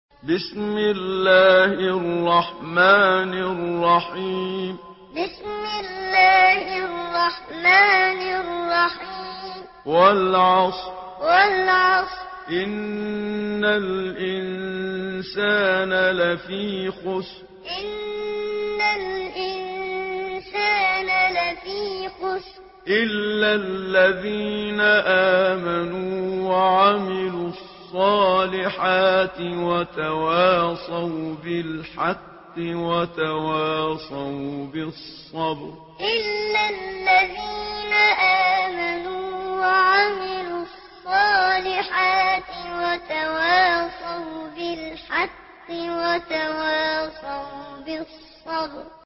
Surah Asr MP3 in the Voice of Muhammad Siddiq Minshawi Muallim in Hafs Narration
Surah Asr MP3 by Muhammad Siddiq Minshawi Muallim in Hafs An Asim narration.